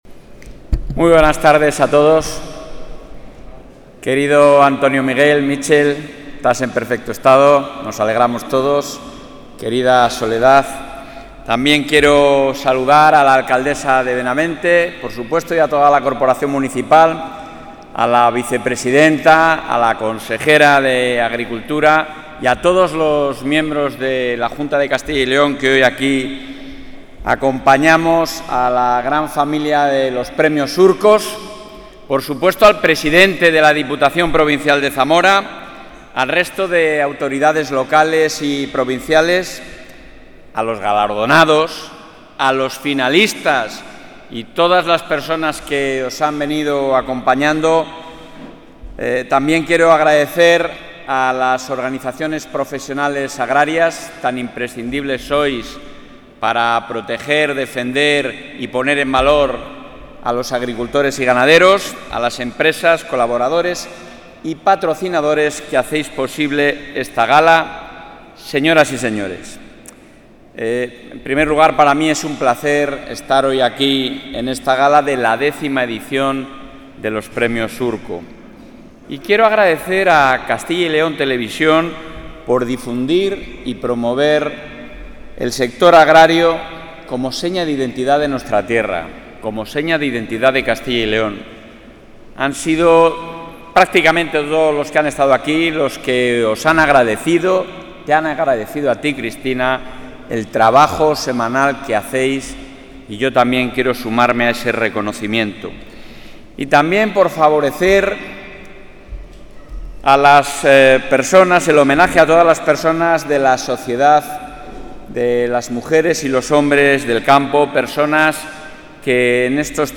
Intervención del presidente de la Junta.
El presidente de la Junta de Castilla y León, Alfonso Fernández Mañueco, ha clausurado hoy en la localidad zamorana de Benavente la X Edición de los Premios Surcos, organizados por Castilla y León Televisión, donde ha avanzado que el Gobierno autonómico publicará mañana la mayor convocatoria de ayudas para la contratación de pólizas de seguros agrarios, dotada con 15 millones de euros.